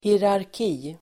Ladda ner uttalet
Uttal: [hierark'i:]